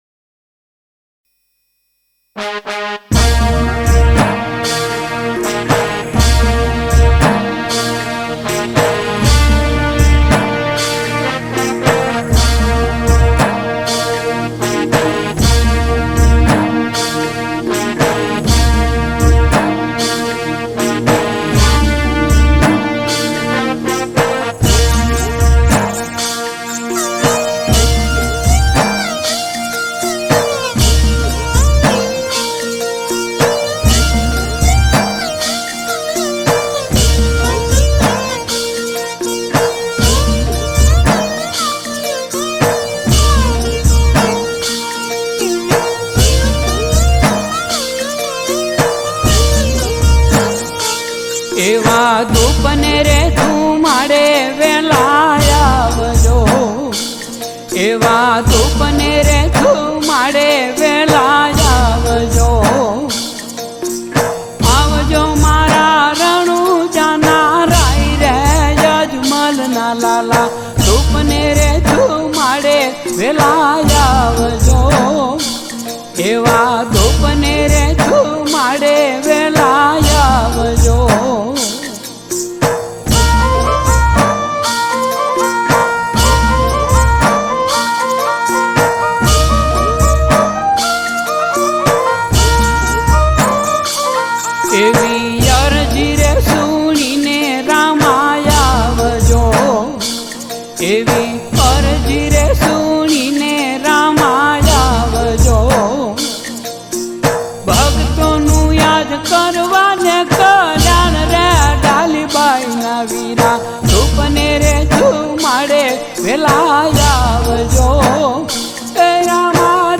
Gujarati Bhajan
Ramdevji Bhajan